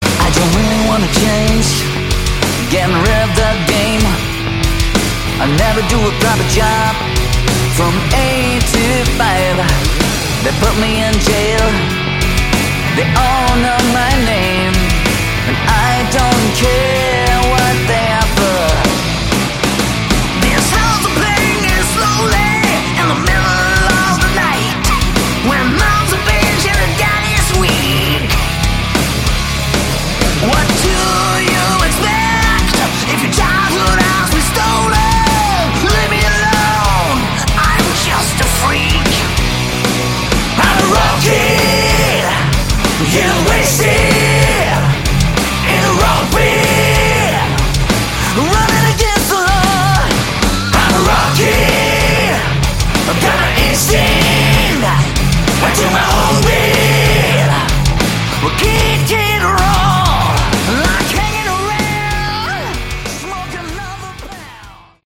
Category: Hard Rock/Melodic Metal
vocals
bass
guitars
keyboards
drums